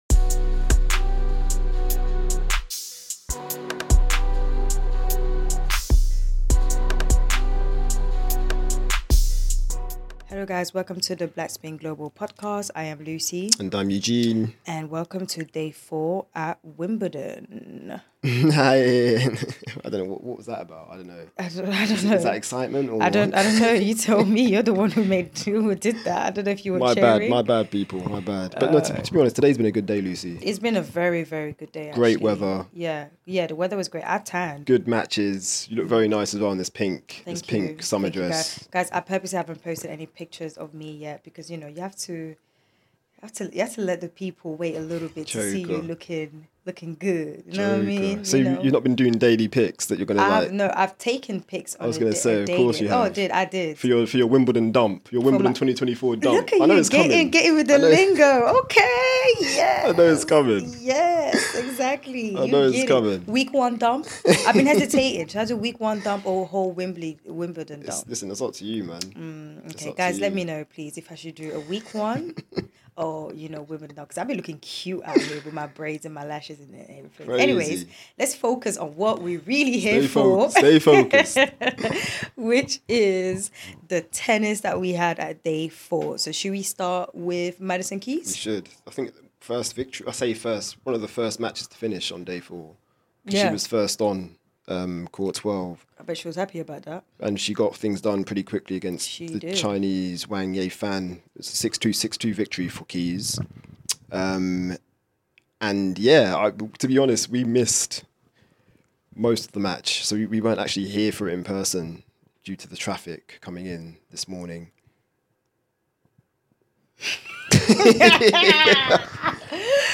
Listen out for interview snippets from Keys, Fils, Shelton and Mpetshi Perricard.